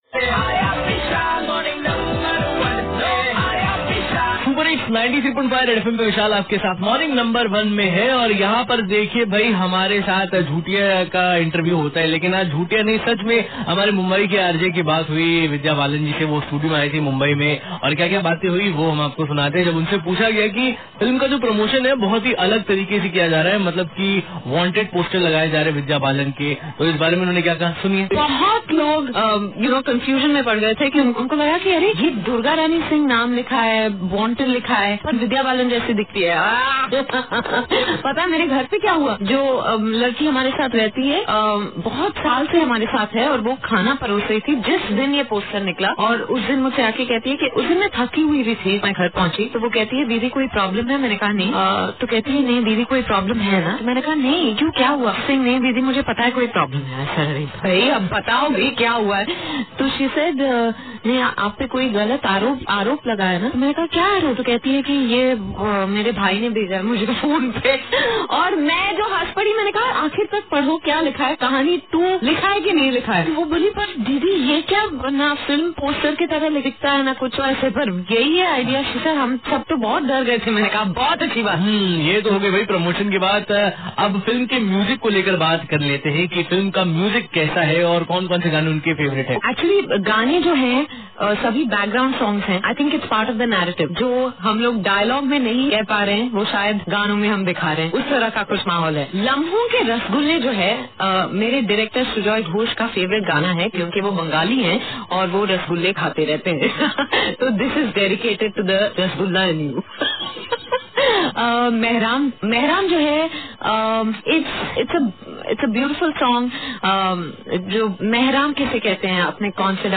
interviewed vidhya balan